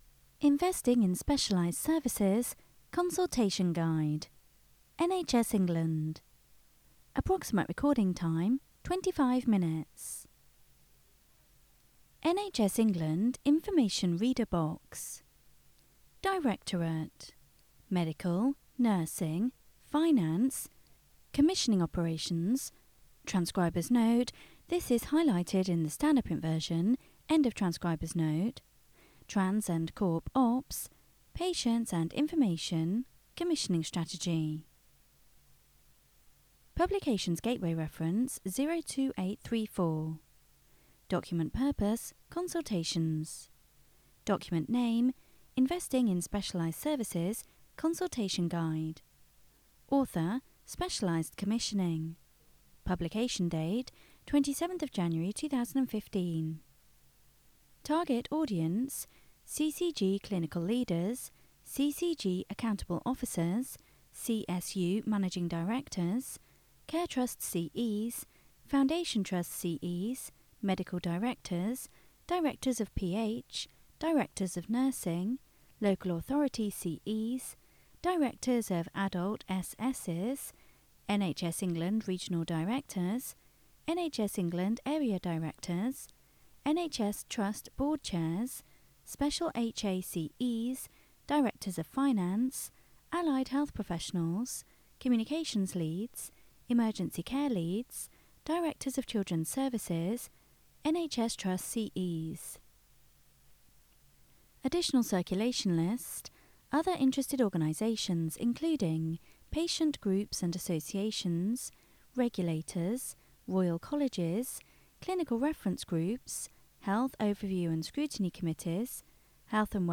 Audio reading of the cosultation document